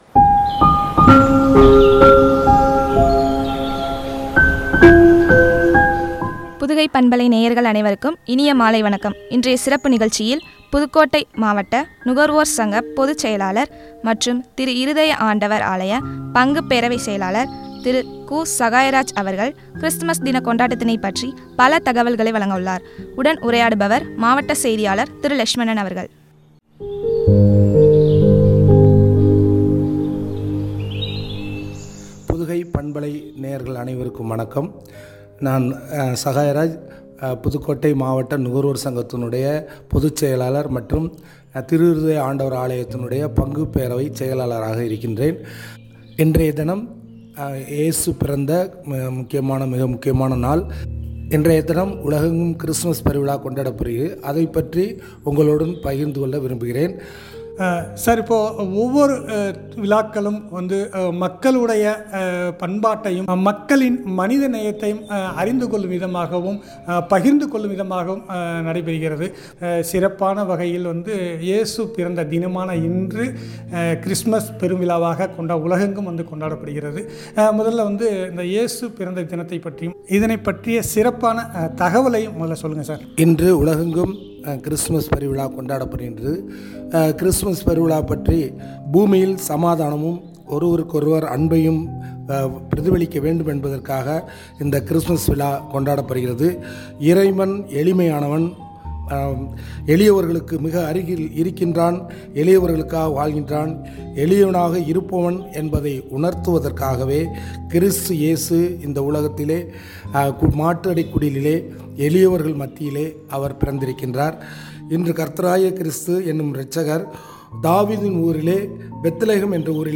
உரையாடல்